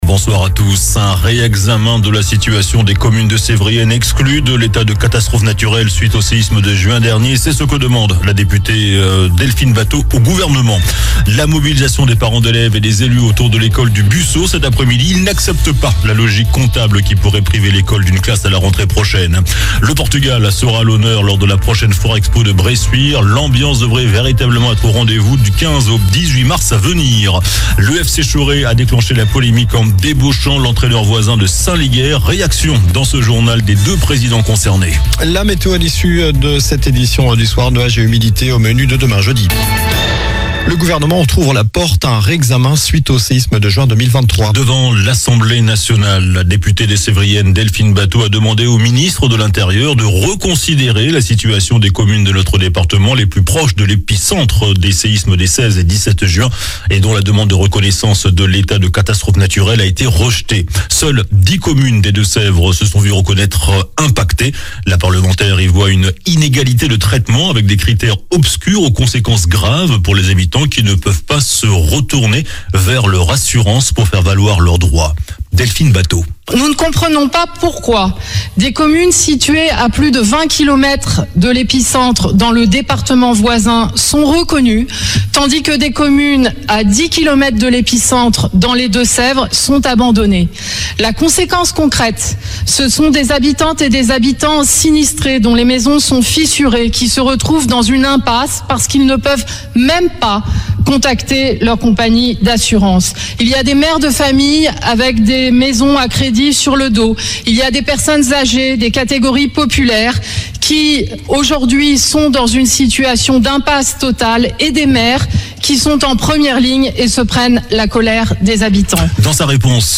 JOURNAL DU MERCREDI 28 FEVRIER ( SOIR )